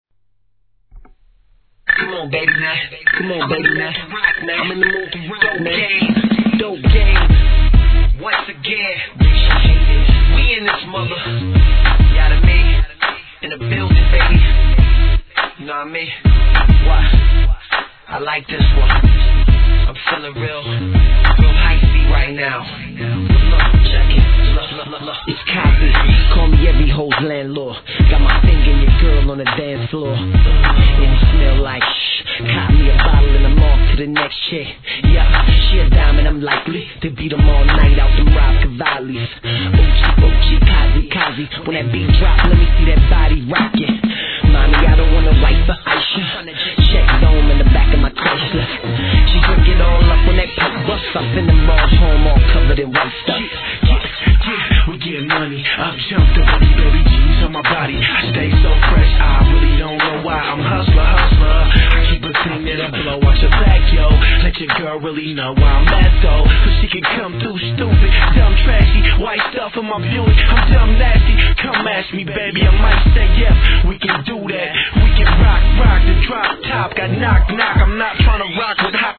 G-RAP/WEST COAST/SOUTH
厚みのあるシンセが効きまくったクラップ音トラックに